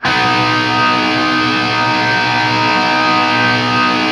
TRIAD B  L-L.wav